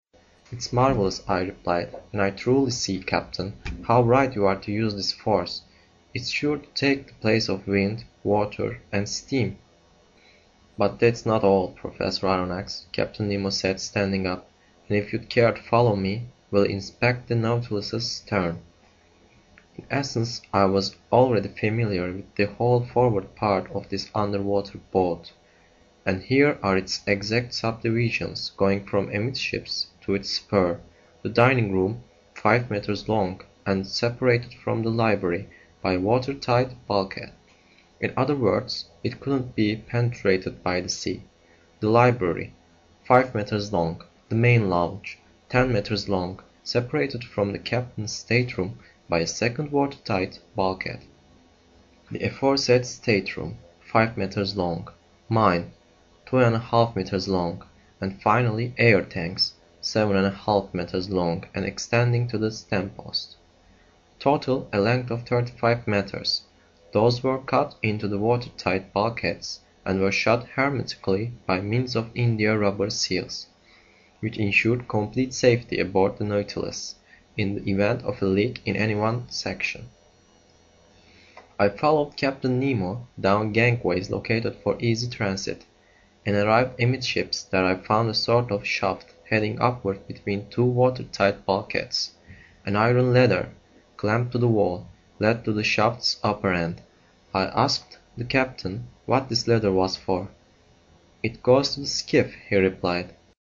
在线英语听力室英语听书《海底两万里》第174期 第12章 一切都用电(7)的听力文件下载,《海底两万里》中英双语有声读物附MP3下载